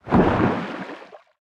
Sfx_creature_glowwhale_attach_01.ogg